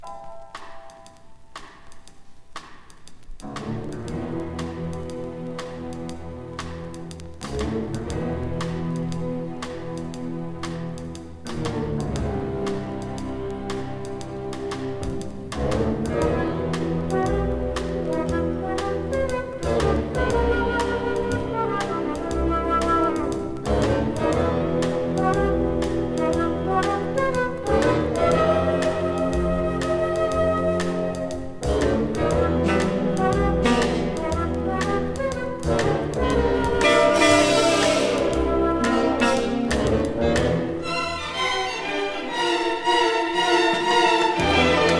耳馴染み良く、豊かに響く演奏に心動かされる良盤です。